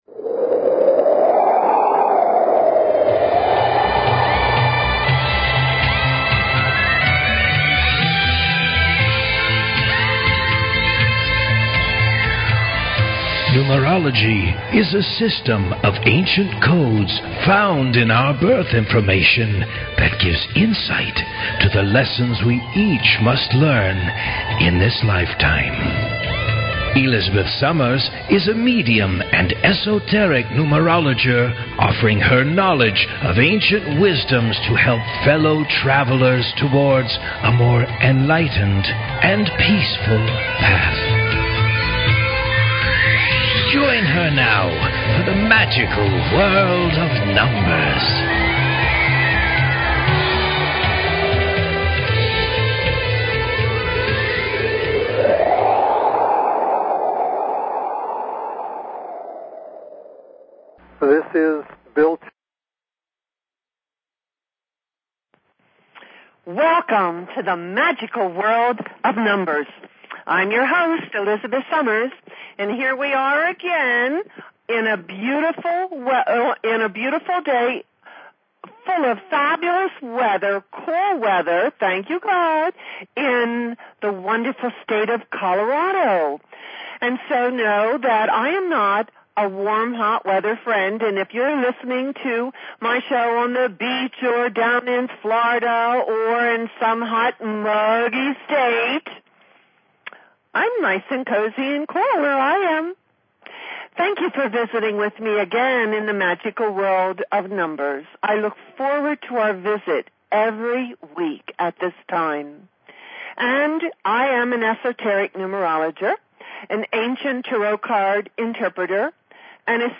Talk Show Episode, Audio Podcast, Magical_World_of_Numbers and Courtesy of BBS Radio on , show guests , about , categorized as